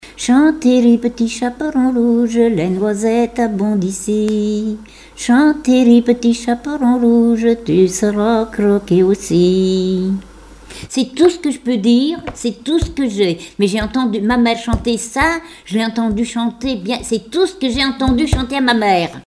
L'enfance - Enfantines - rondes et jeux
Témoignages et chansons
Pièce musicale inédite